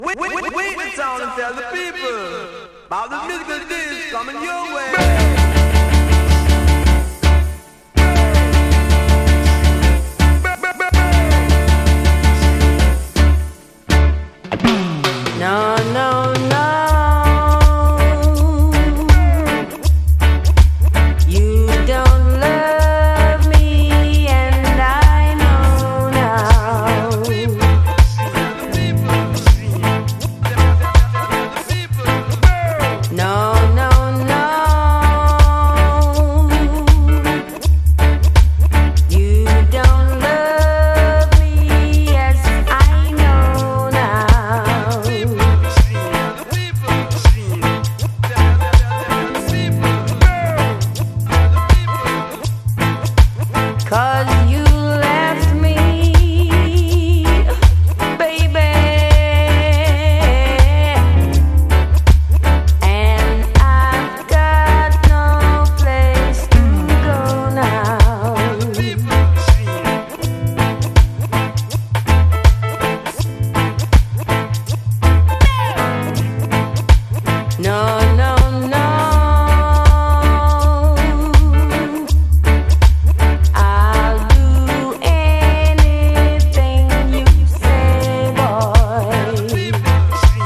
レゲエやダンスホール界のみならず、ヒップホップの現場でもプライされまくったビッグ・チューン。